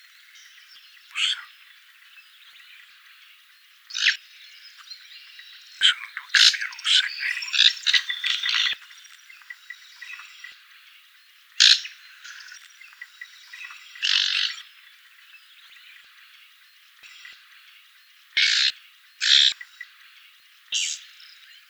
Lanius senator - Woodchat shrike - Averla capirossa